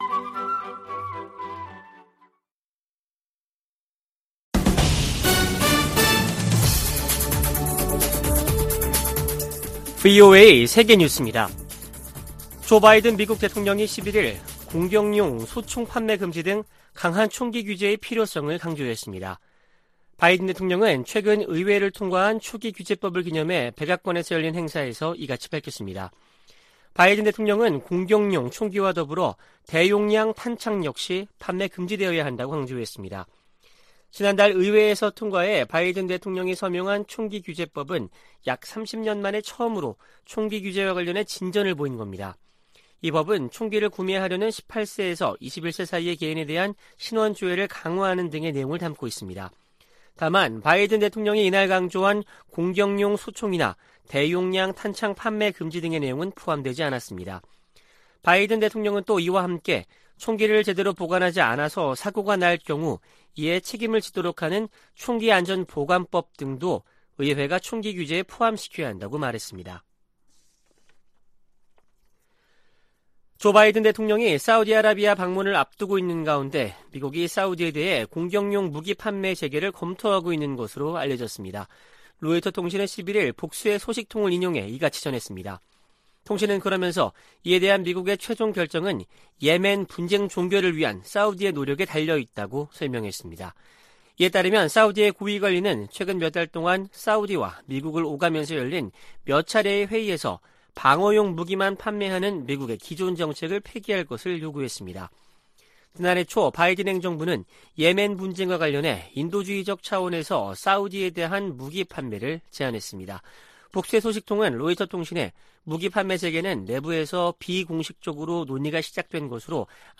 VOA 한국어 아침 뉴스 프로그램 '워싱턴 뉴스 광장' 2022년 7월 12일 방송입니다. 북한이 한 달 만에 또 다시 서해로 방사포 2발을 발사했습니다. 미-한-일은 주요 20개국(G20) 외교장관 회의를 계기로 3자 회담을 갖고 안보협력 확대 방안을 협의했습니다. 미국과 한국이 다음달 22일부터 9월 1일까지 미-한 연합지휘소훈련(CCPT)을 진행하기로 했습니다.